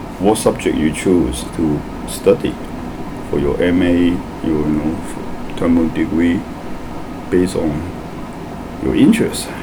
S1 = Hong Kong male S2 = Malaysian female Context: S2 asked S1 about why he chose to become an artist, and he is talking about the choice of subject for one's studies.
The word terminal is pronounced as [tɜːmu] , i.e. with two syllables, and with final L-vocalisation, so the syllabic /l/ is pronounced as vocalic [u].